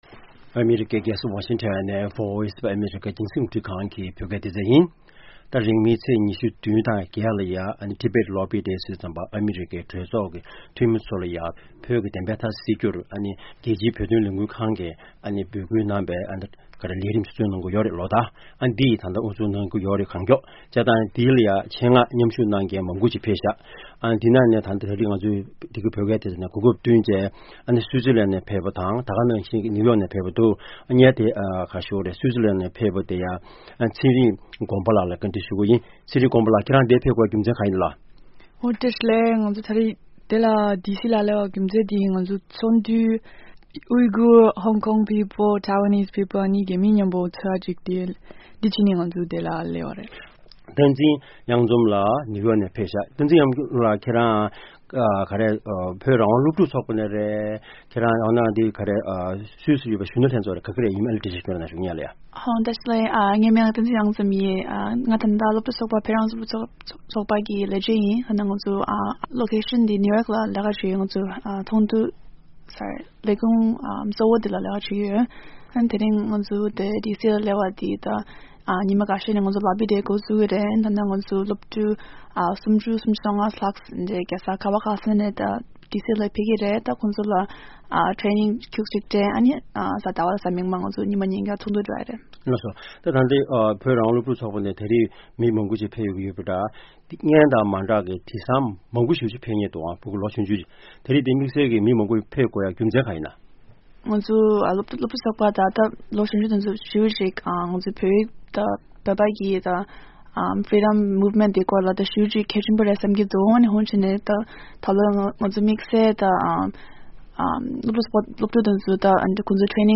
Interview with SFT about Tibet Lobby